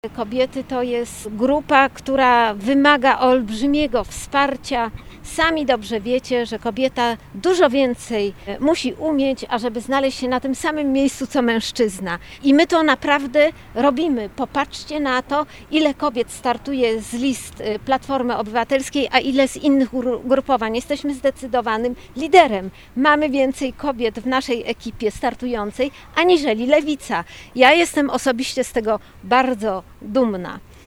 Konferencja prasowa odbyła się z udziałem wszystkich kandydatów i kandydatek KO.
-Jesteśmy liderem pod względem kobiet na liście, mówi senator Alicja Chybicka, która będzie jedynką do Sejmu w okręgu wrocławskim.